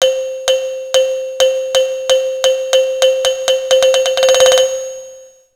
Index of /phonetones/unzipped/Motorola/PEBL-VU20/System Sounds/Camera
Timer_5sec.wav